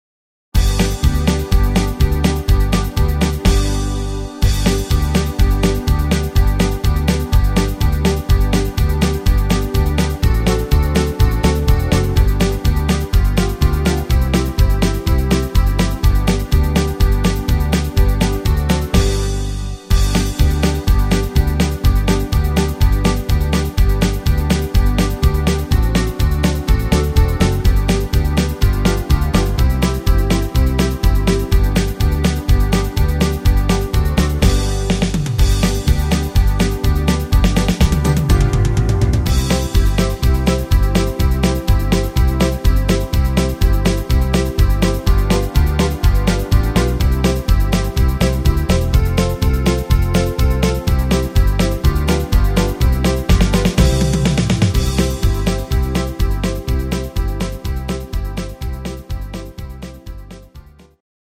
instr. Saxophon
Rhythmus  Country Shuffle
Art  Instrumental Sax